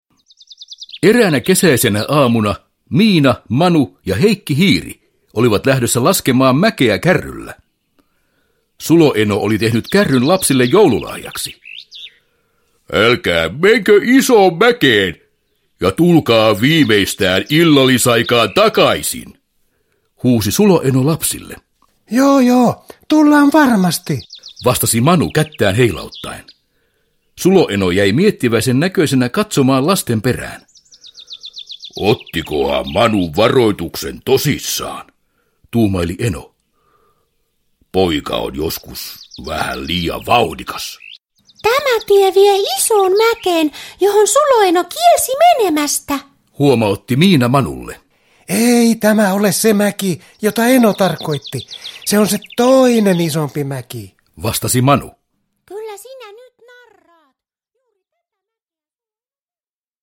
Miina ja Manu pajassa – Ljudbok – Laddas ner